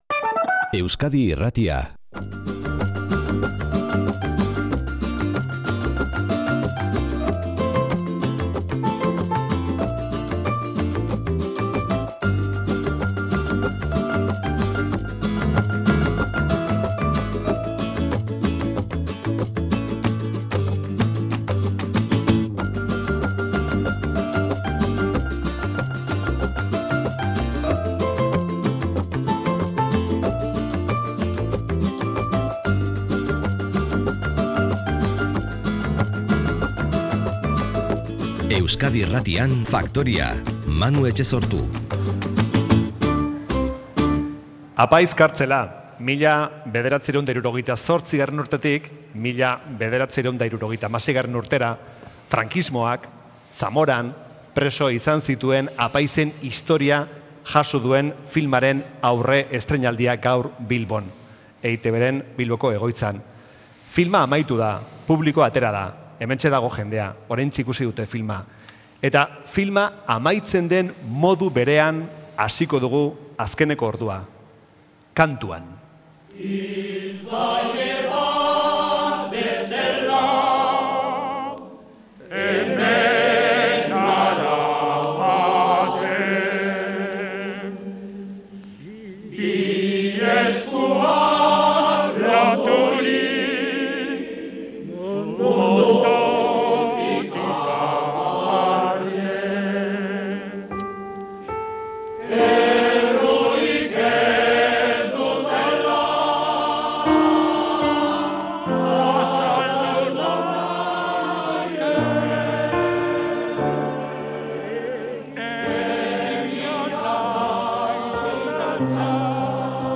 Audioa: Unkituta eta inpresio onarekin atera dira ikusleak Bilboko EiTBren Multibox aretotik.